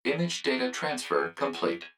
042_IMG_Transfer_Complete.wav